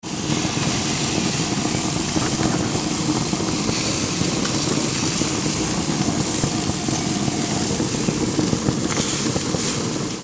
Feuer & Grill Sounds - Freie Verwendung
Gasgrill A.mp3